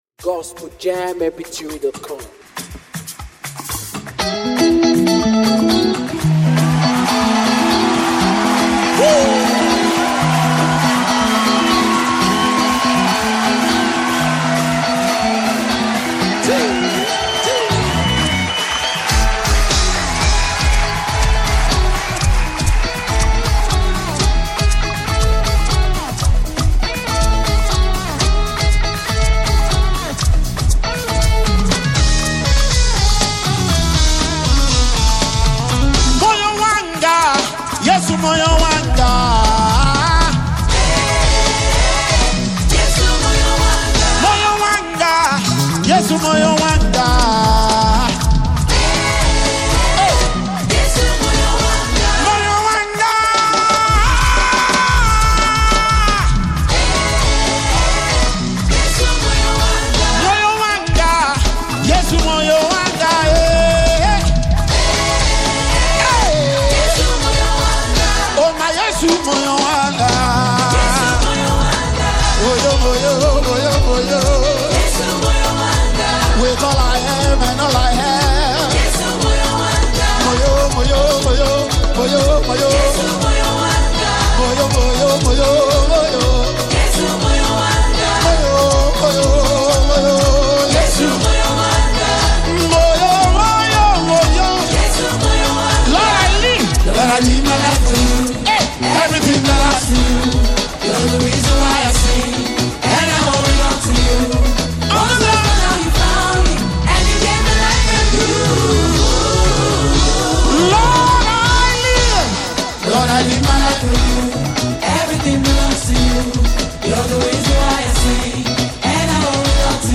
powerful gospel song